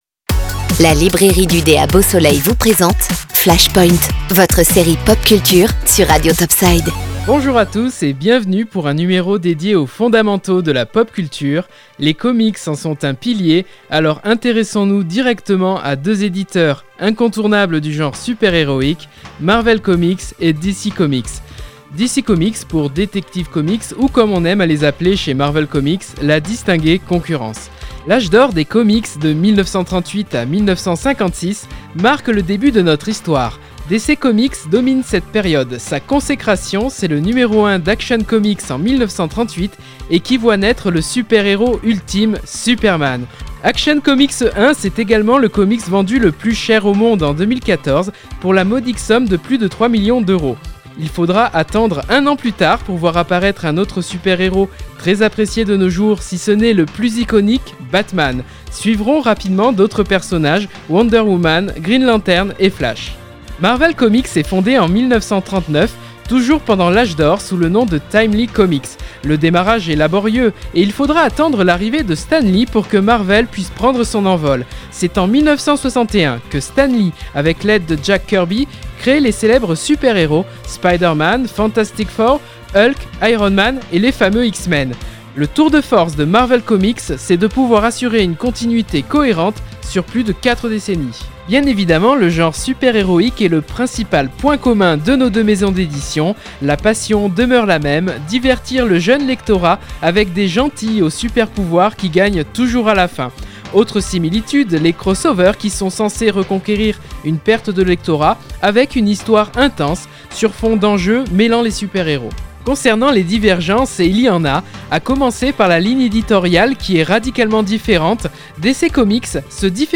Flash Point chronique mission consacrée à la pop culture (bandes dessinées de type comics/mangas, films, séries, jeux vidéo des années 80 à aujourd’hui…) sur Radio Top Side tous les vendredis entre 18h et 19h.